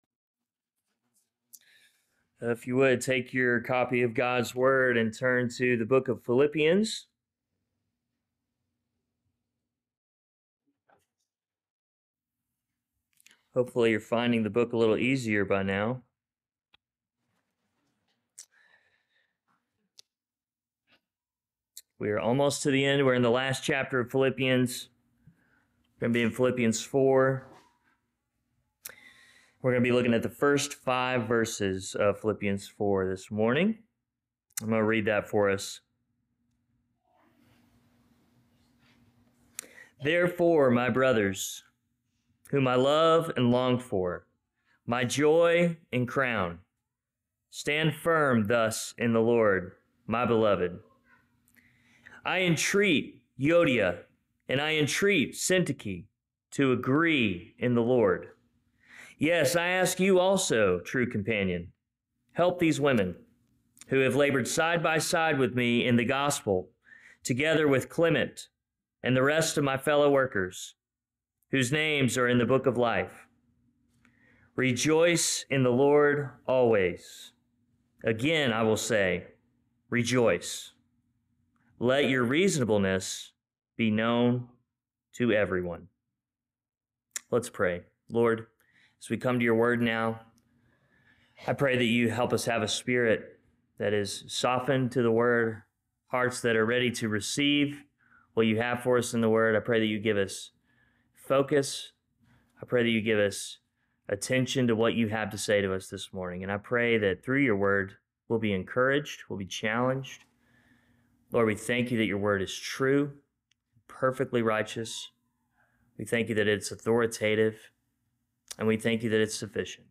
Sermons | Mascot Baptist Church